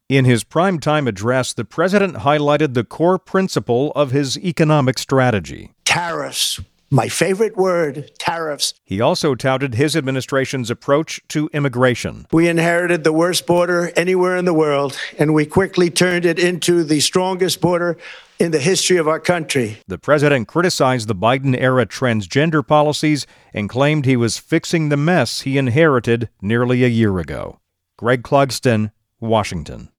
President Trump highlighted his immigration and economic policies in a prime time speech to the nation.